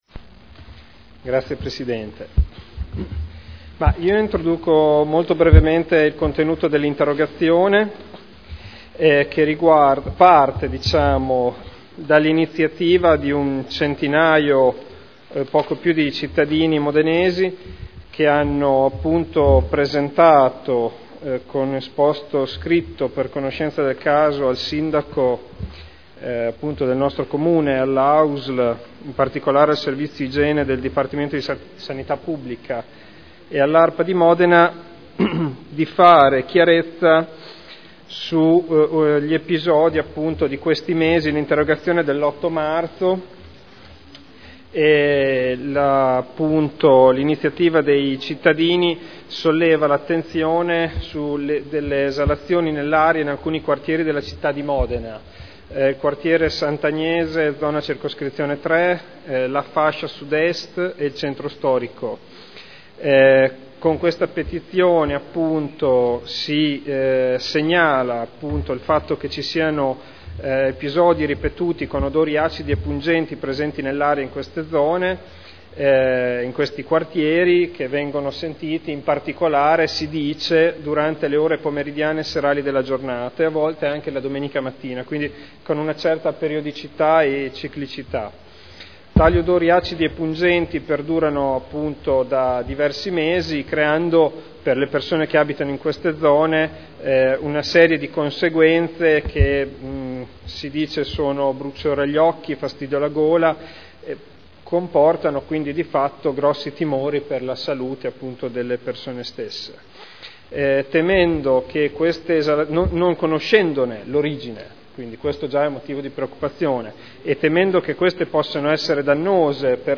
Seduta del 30/05/2011. Interrogazione del consigliere Ricci (Sinistra per Modena) avente per oggetto: “Esalazione nell’aria”